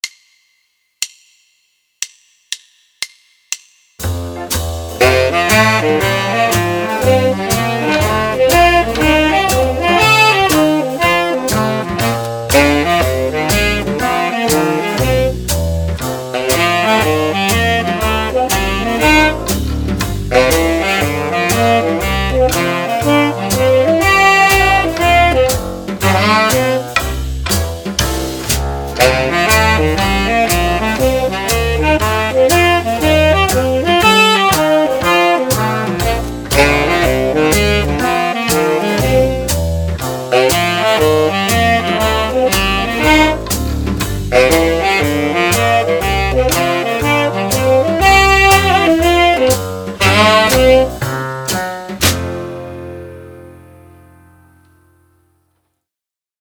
Here is a slowed-down version you can play along with: